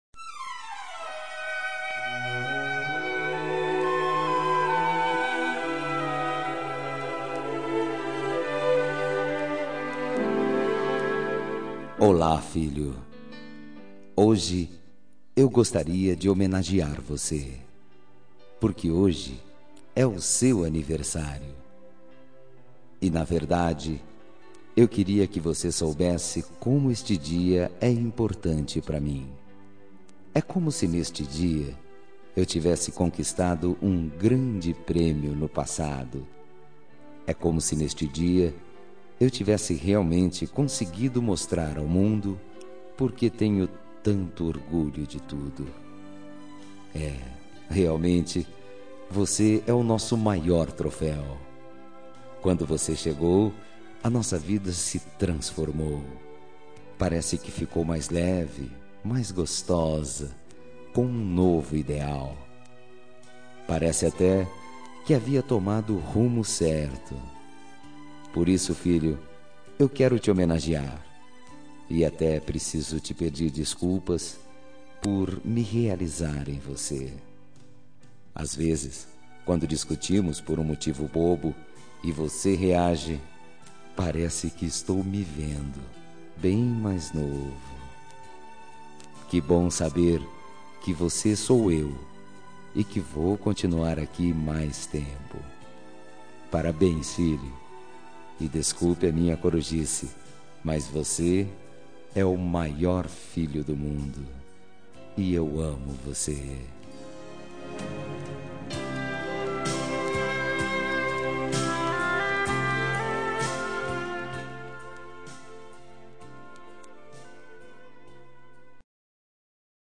Telemensagem de Aniversário de Filho – Voz Masculina – Cód: 18520 – Linda